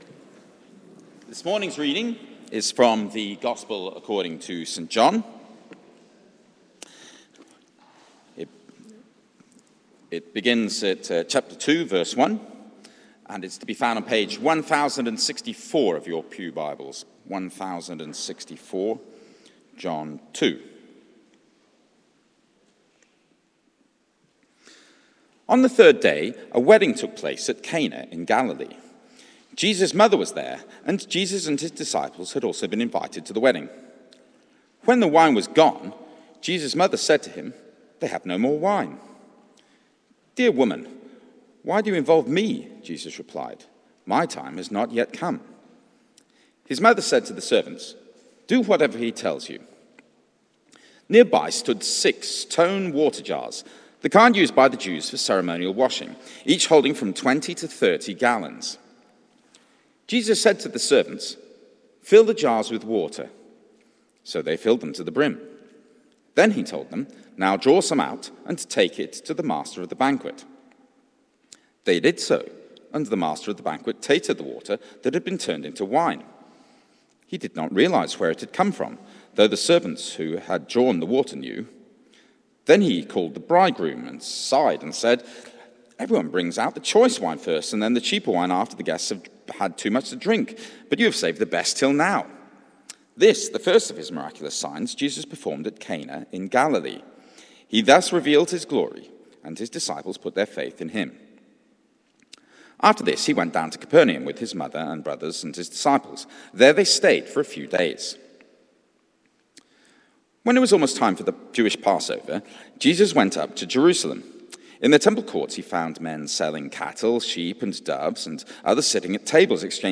The Wine and the Whip Sermon